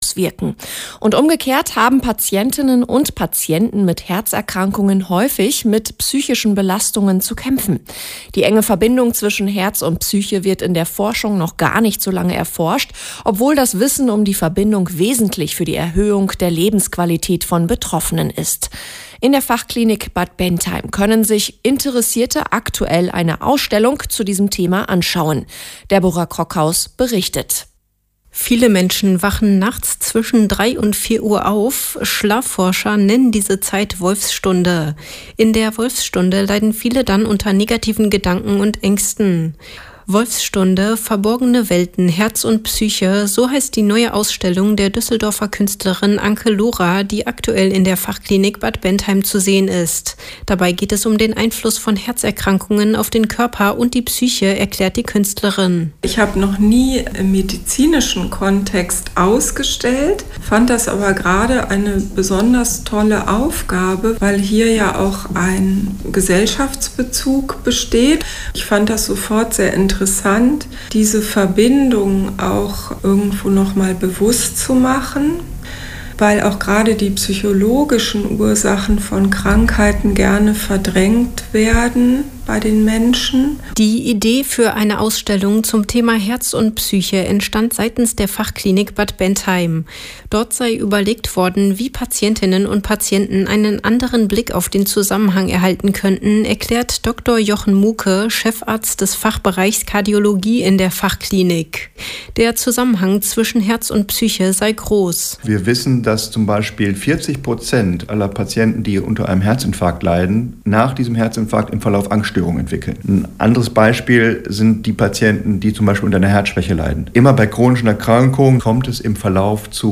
Die Ems-Vechte-Welle zu Gast in der Fachklinik